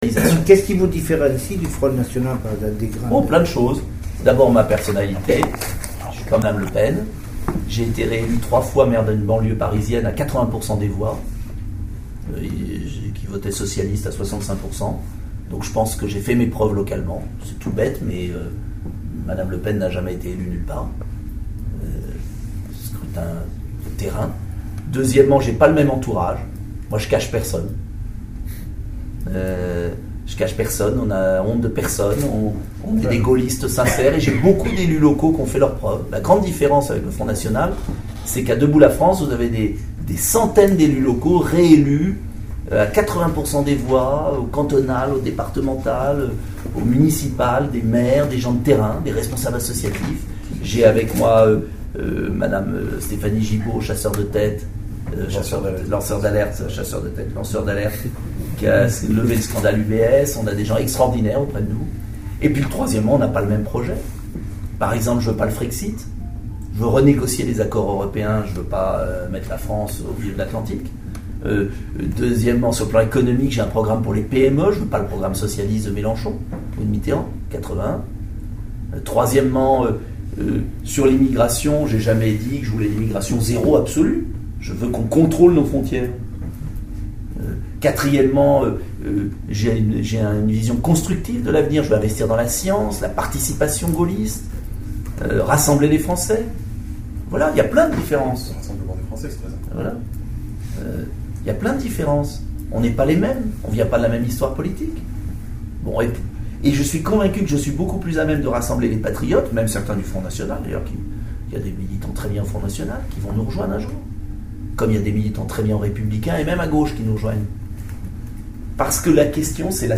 La conférence de presse
A son arrivée à l’aéroport de Tarbes-Lourdes-Pyrénées, Nicolas Dupont-Aignan a tenu une conférence de presse dans une salle de l’aérogare d’affaires.